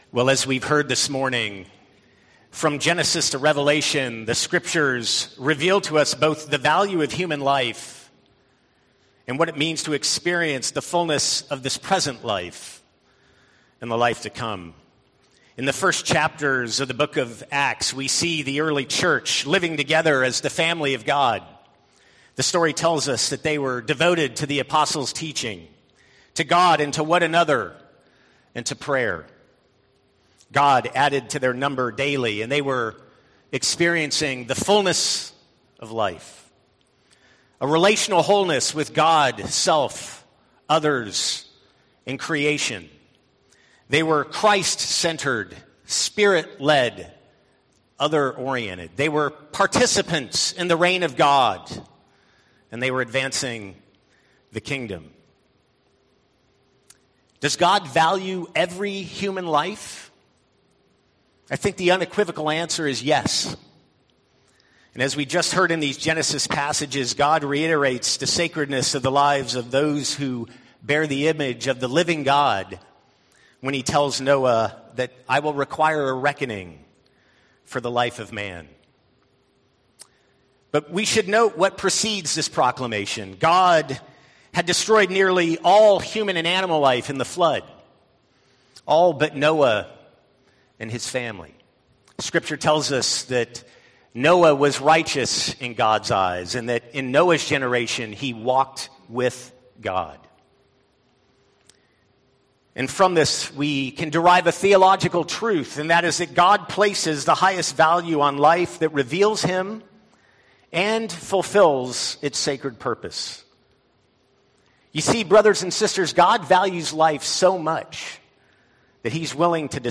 Sermon1.20.19.mp3